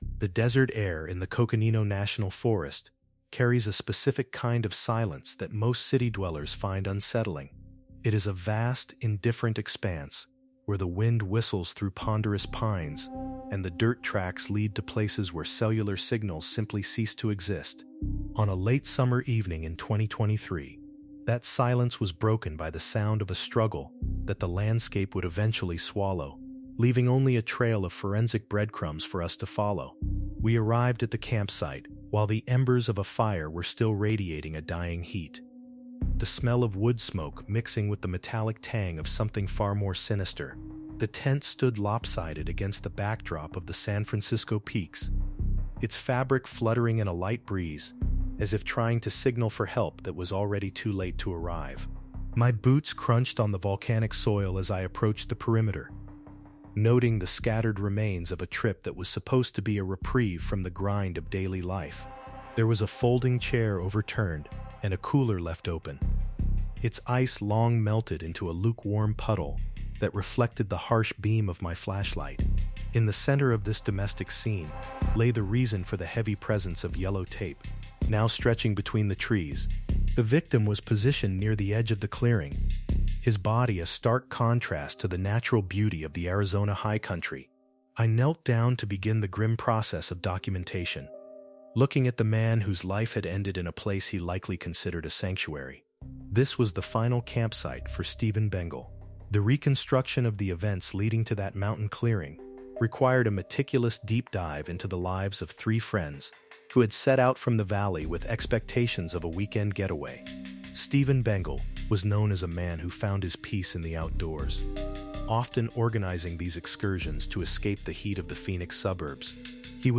Through first-person detective narration, we break down the forensic evidence, digital footprints, and the meticulous 3D mapping of the crime scene that eventually exposed the truth behind the "murder tape" of forensic traces left in the volcanic soil.